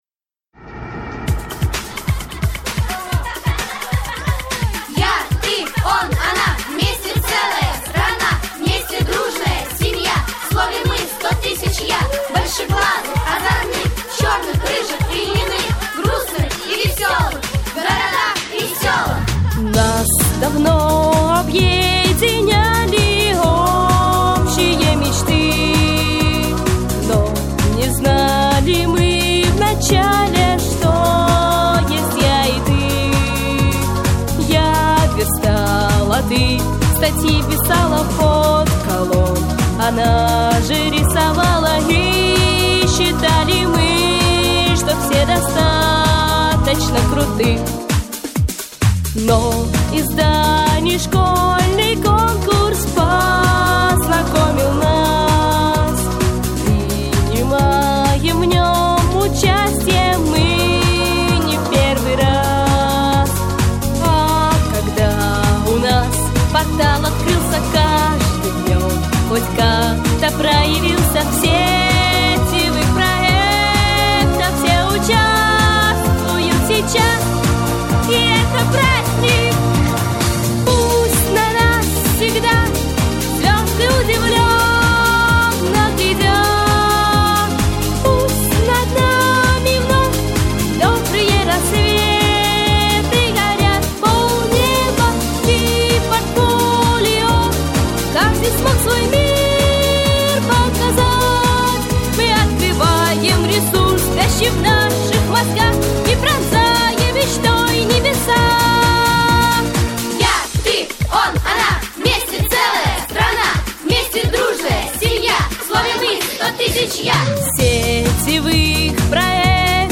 Прослушать минусовку